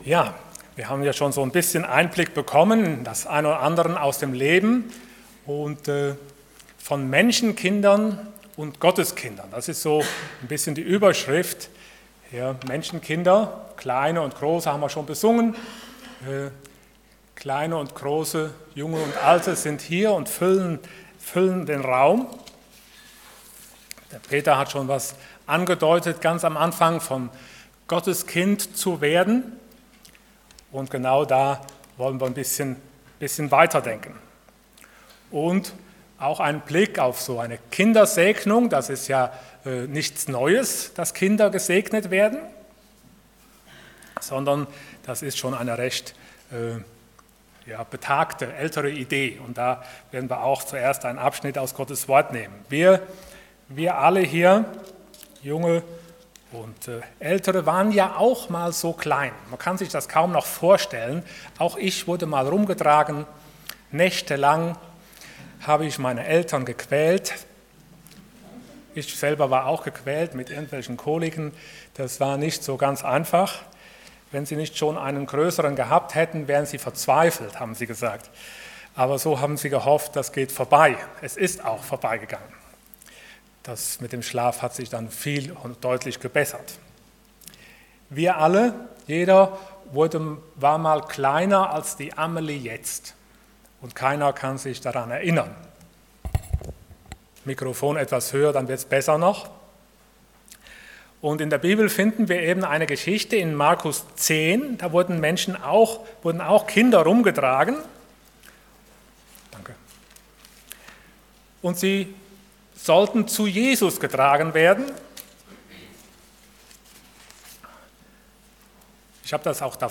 Allgemeine Predigten Passage: Mark 10:13-16 Dienstart: Sonntag Morgen